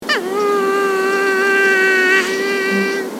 Index of /userimages/file/Voice/animals
vidra.mp3